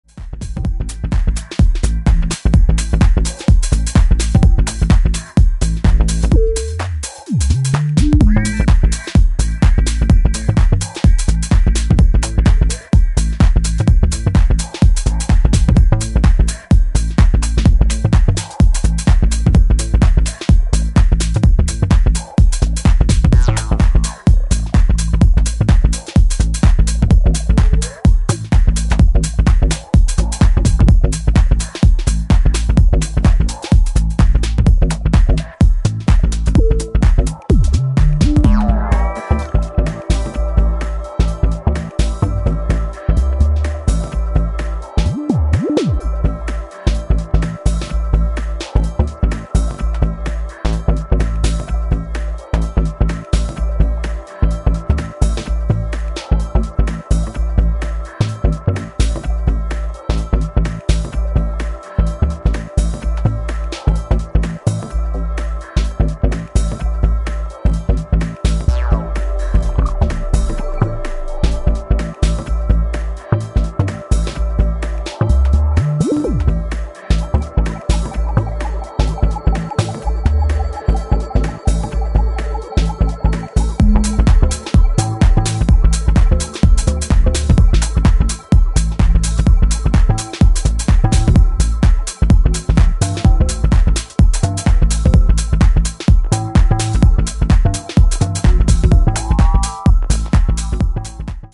subtly minimal roller
taking a more electro edged approach
bubbles along on a tide of energetic percussion